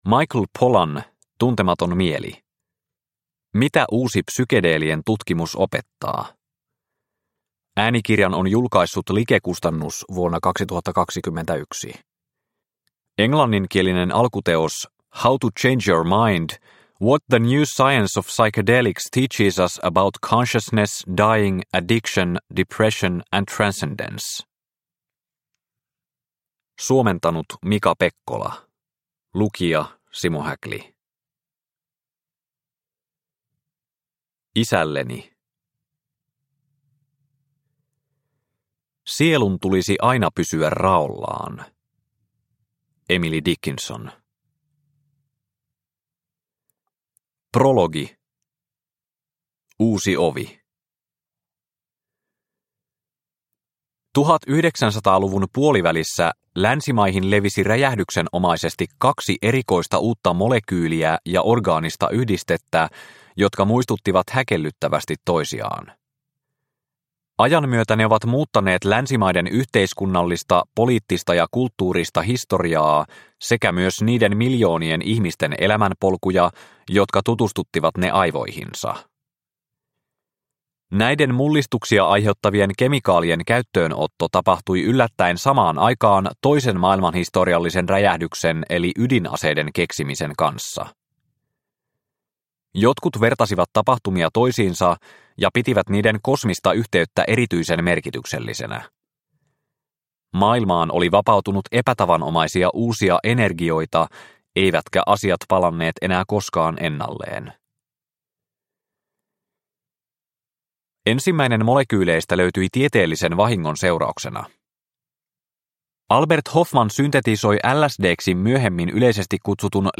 Tuntematon mieli – Ljudbok – Laddas ner